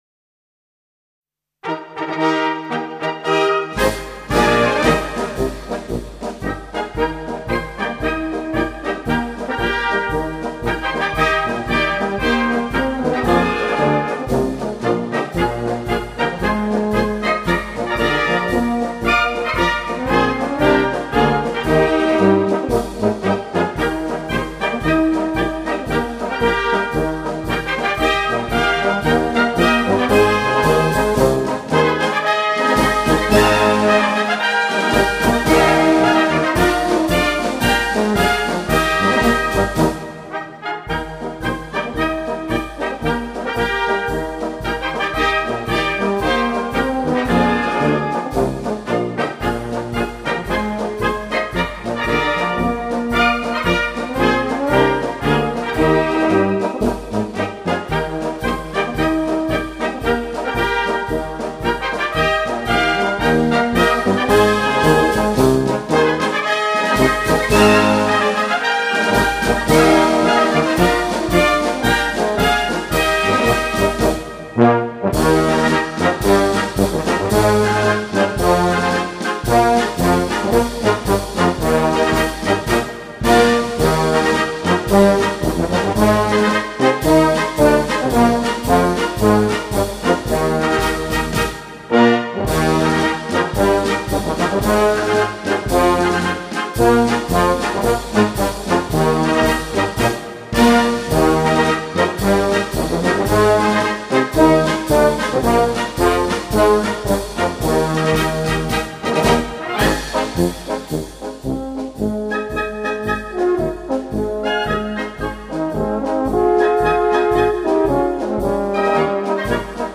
Gattung: Jubiläumsmarsch
Besetzung: Blasorchester